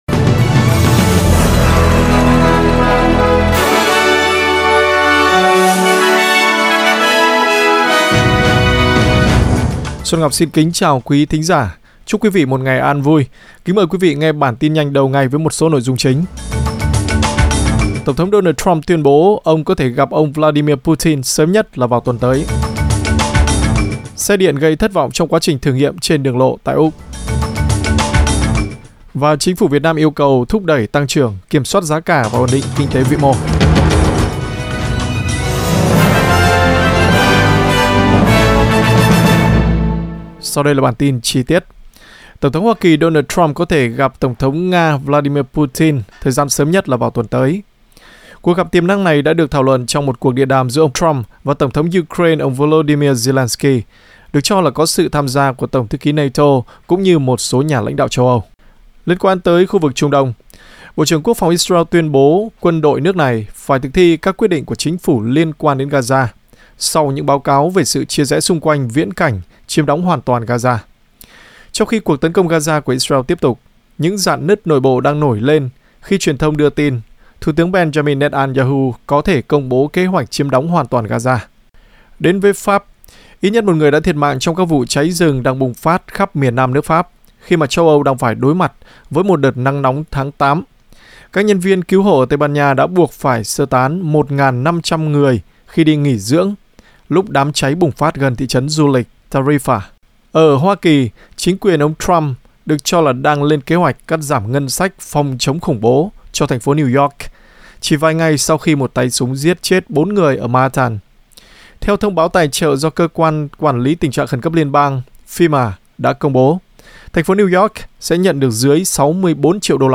Vietnamese news bulletin Source: Getty